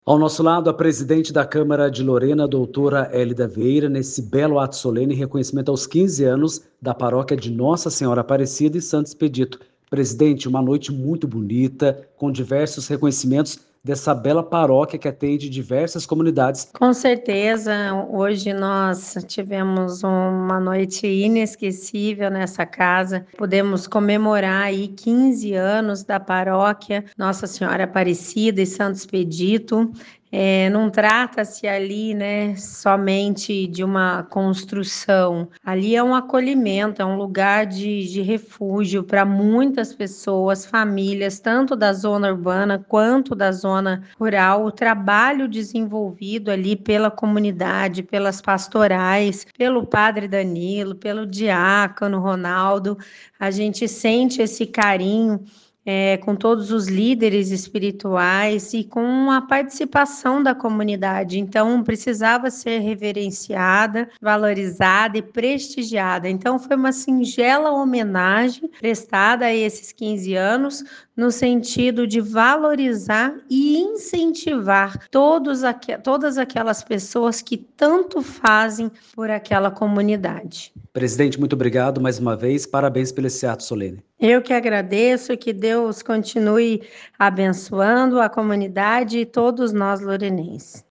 Entrevistas (áudios):